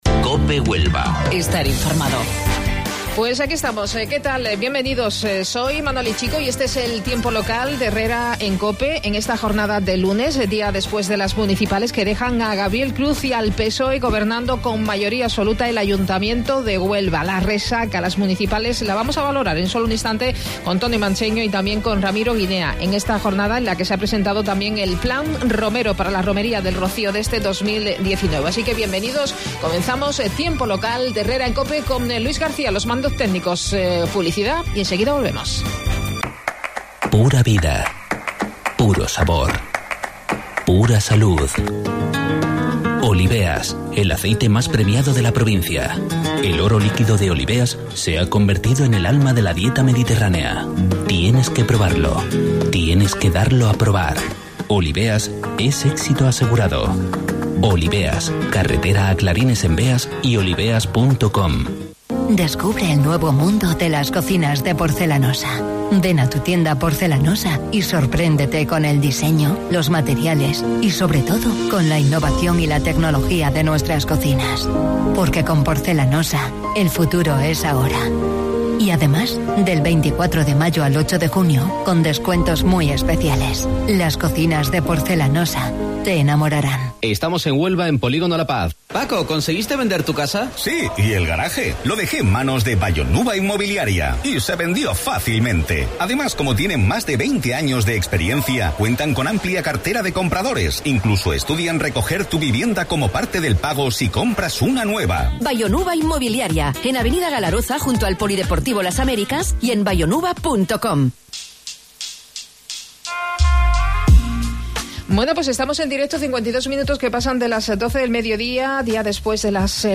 AUDIO: Tertulia valoración municipales en Huelva